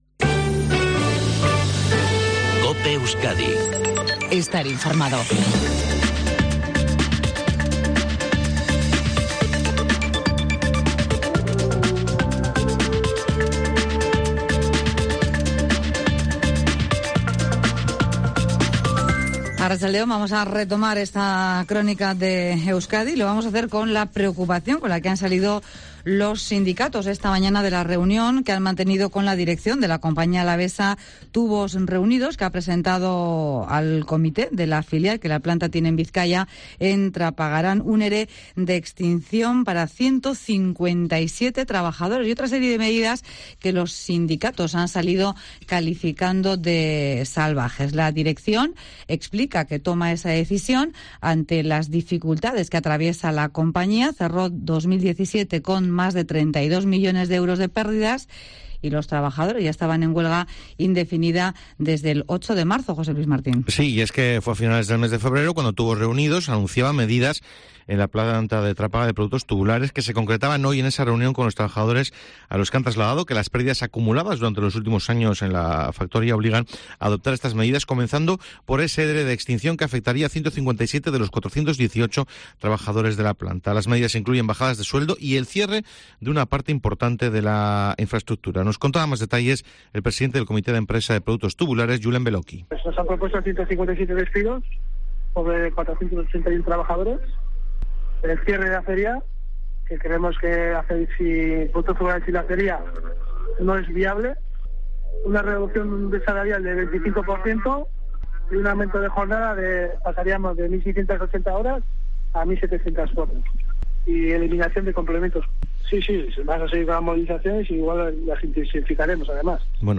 INFORMATIVO EUSKADI MEDIODIA 14 48 A 15 00.mp3